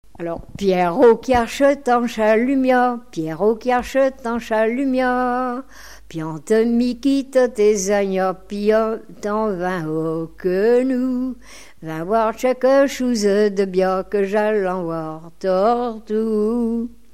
chant de Noël
Pièce musicale inédite